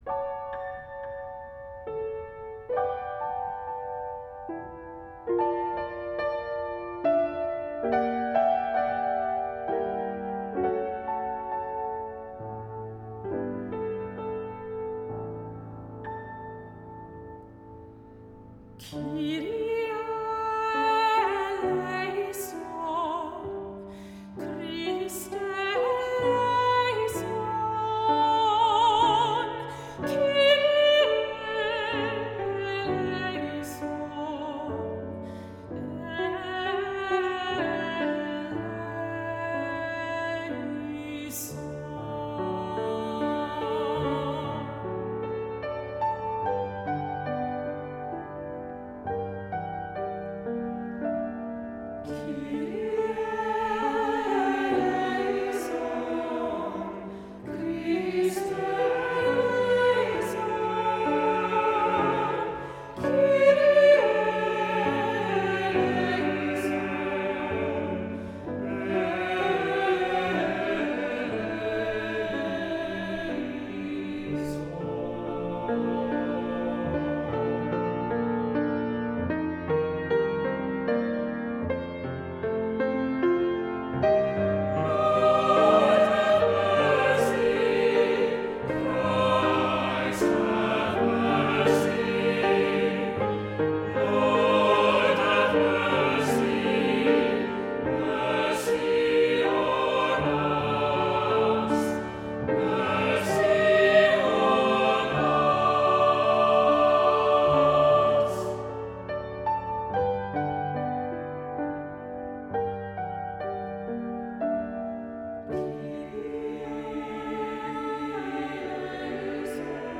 Voicing: "SATB","Solo"